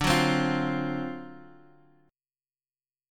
D7#9 chord